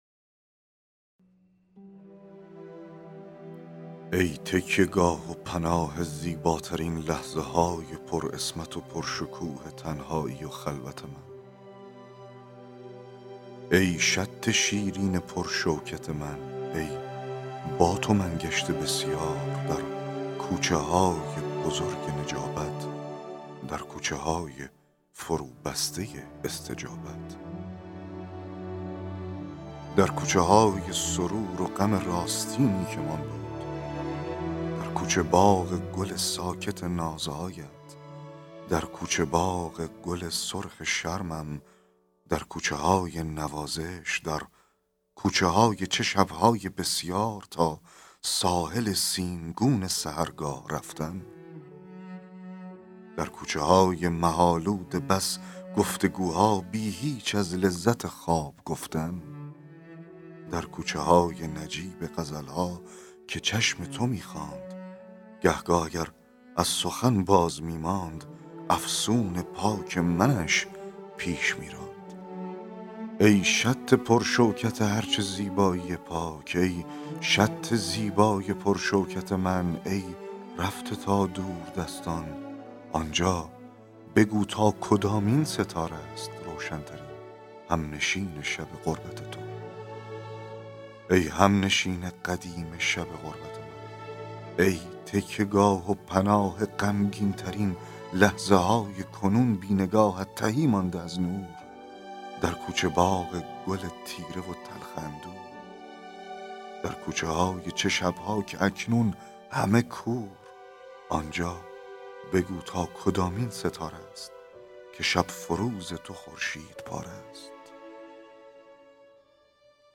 فایل صوتی دکلمه شعر ای تکیه گاه و پناه (غزل 3)